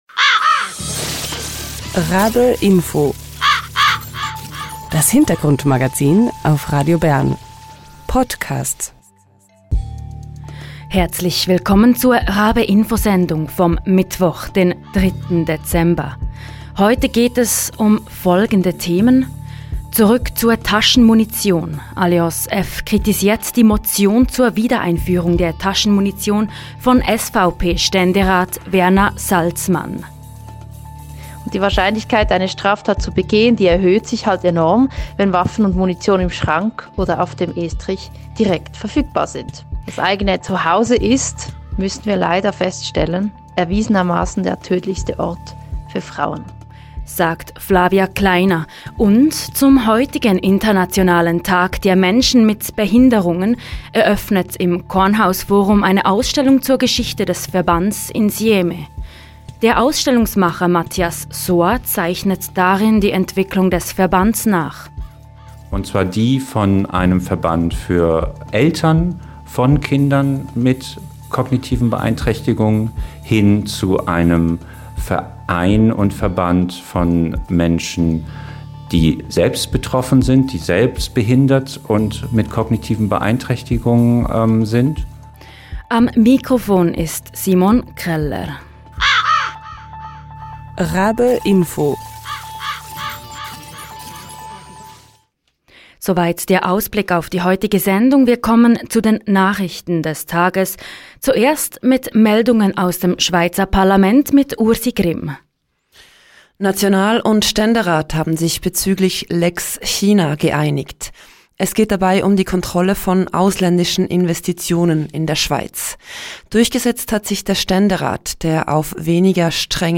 Beschreibung vor 4 Monaten Zurück zur Taschenmunition? alliance F kritisiert die Motion zur Wiedereinführung der Taschenmunition von SVP-Ständerat Werner Salzmann. Im Interiew